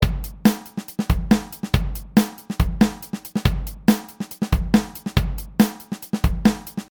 I’m going to make the beat repeat for four bars, but add a bit of variation to the second bar and a little fill at the end.